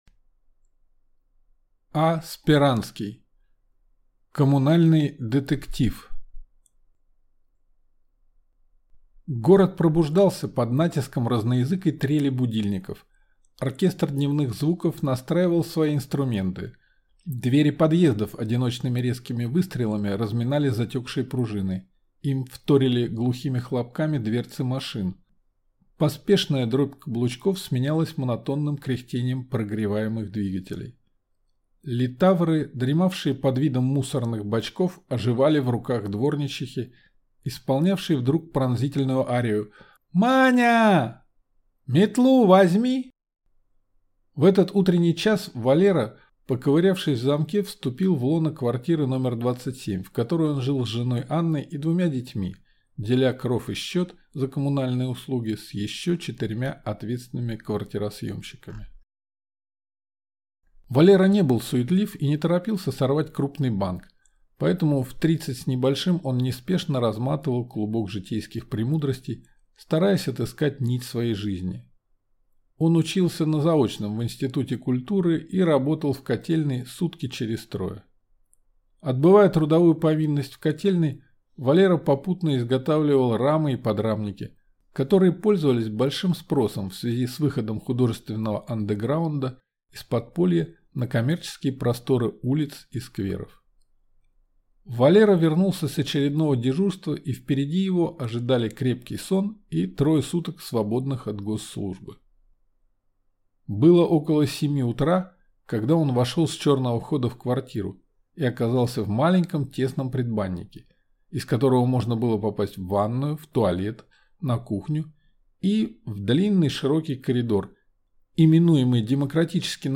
Аудиокнига Коммунальный детектив | Библиотека аудиокниг